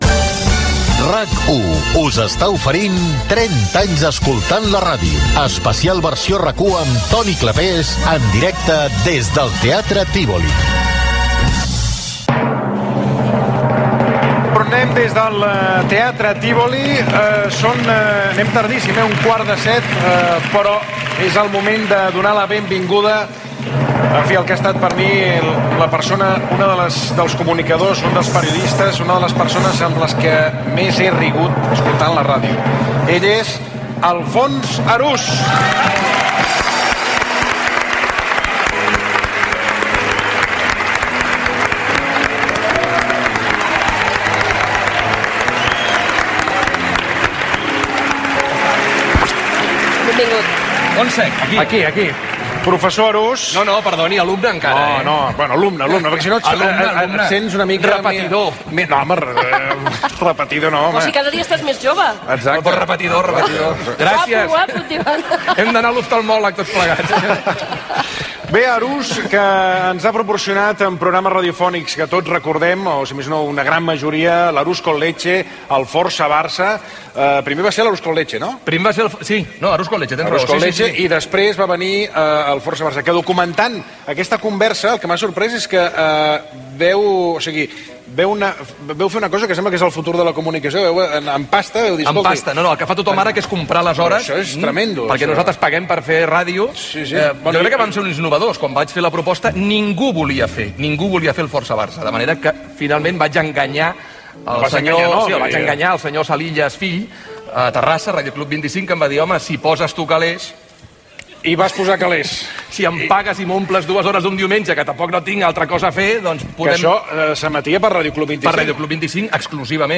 Especial 30 anys escoltant la ràdio, des del Teatre Tívoli de Barcelona. Entrevista a Alfons Arús, repassant alguns dels seus programes.
Entreteniment